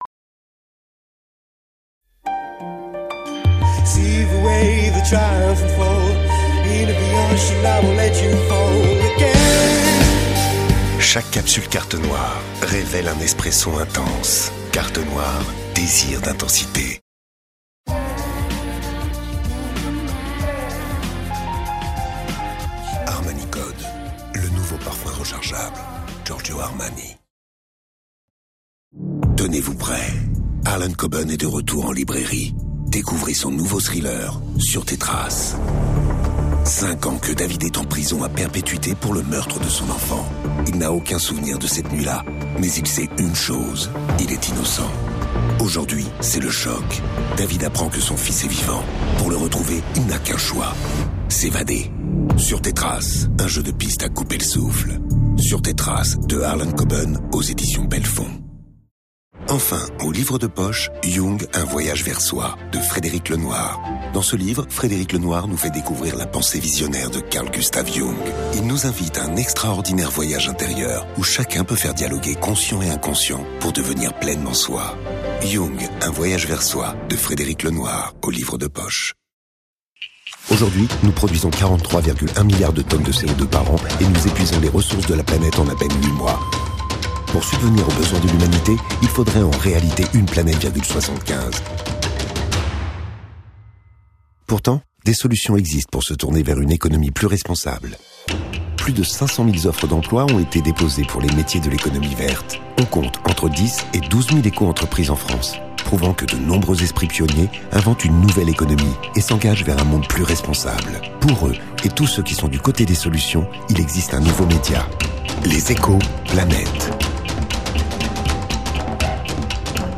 Voix off
36 - 62 ans - Baryton